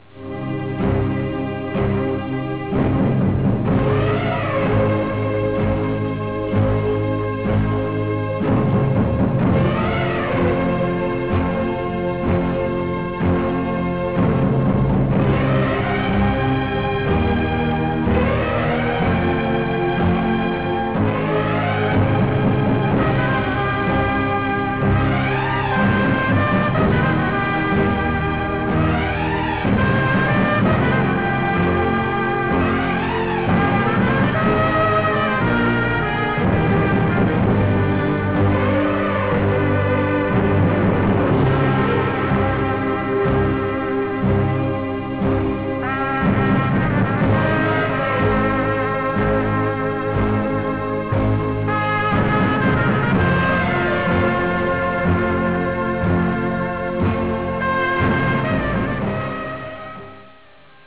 Original Track Music